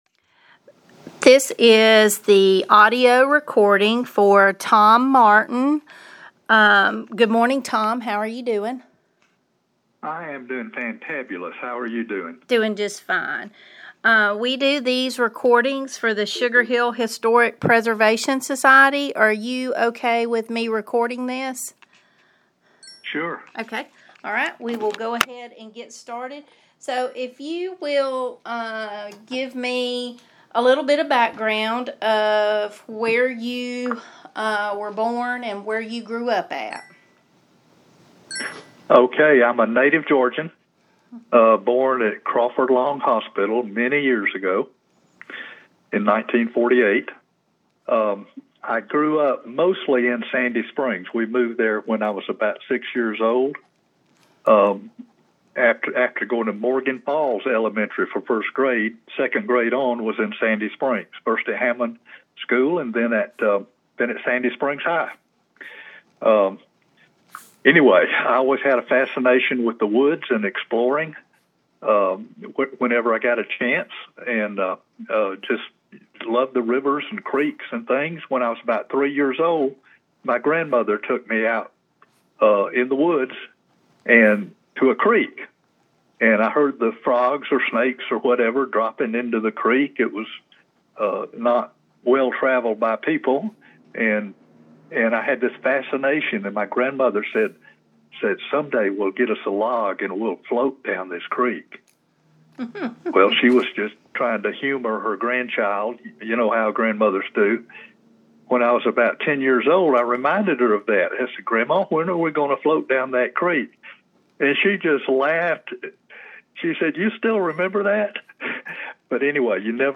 Oral histories
via telephone